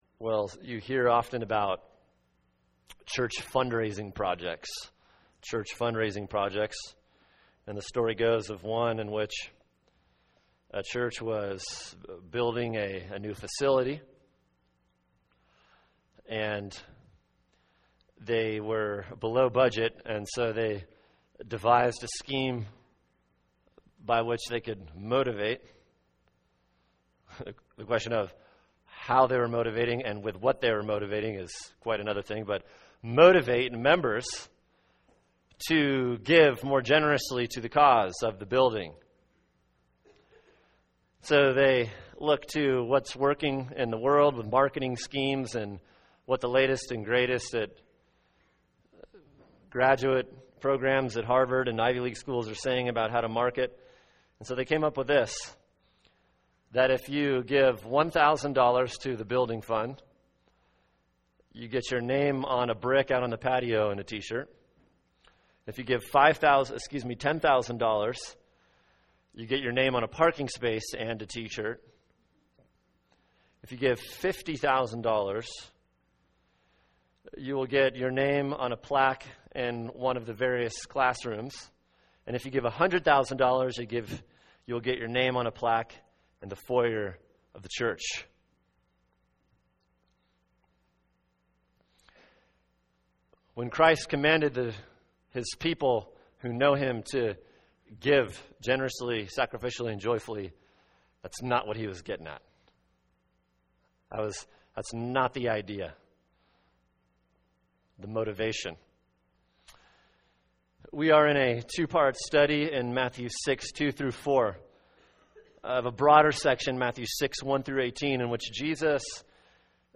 [sermon] Matthew 6:2-4 “Religiatrics and Giving” Part 2 | Cornerstone Church - Jackson Hole